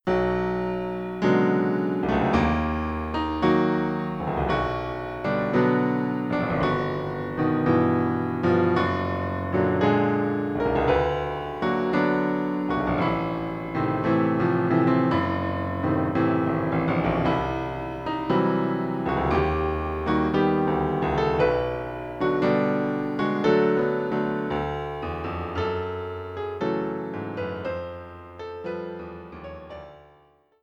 Instrumentation: Piano